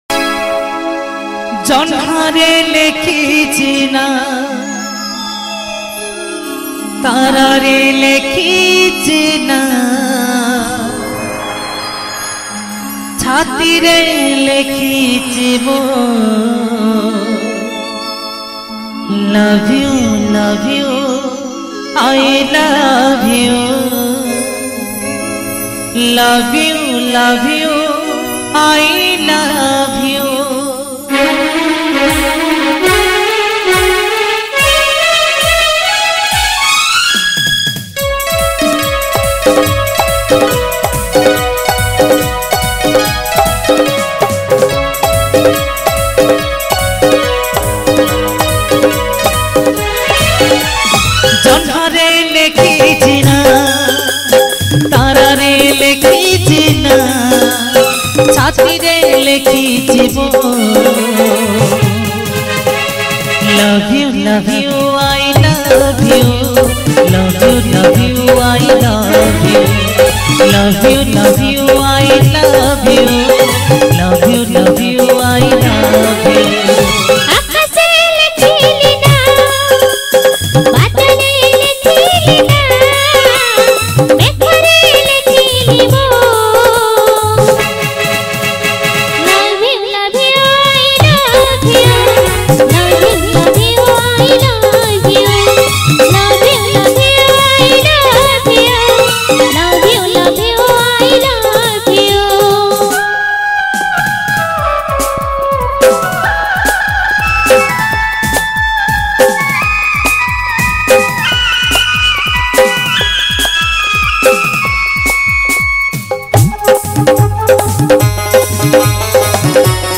Odia Jatra Song